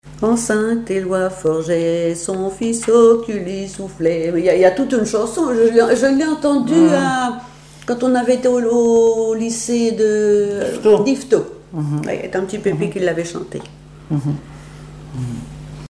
Genre brève
Cantiques, chants paillards et chansons
Pièce musicale inédite